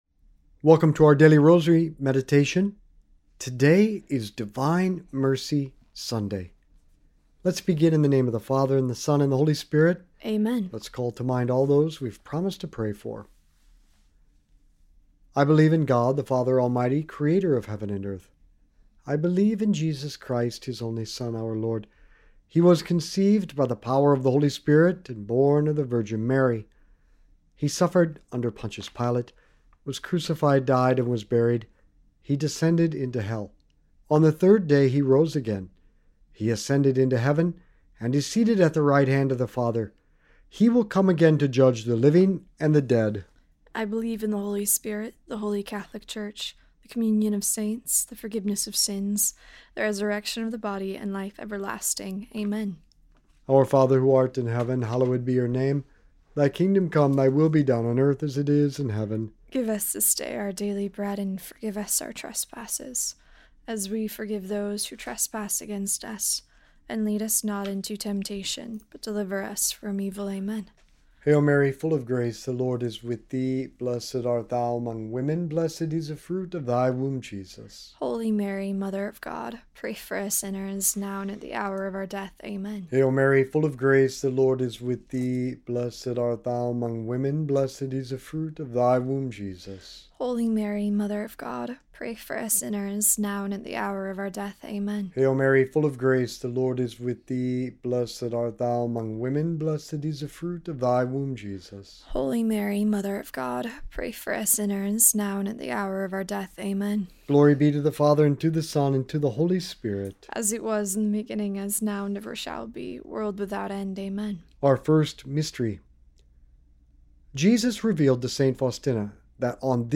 This episode is a Daily Rosary Meditation focused on Divine Mercy Sunday, featuring traditional Catholic prayers including the Creed, Our Father, Hail Mary, and rosary mysteries.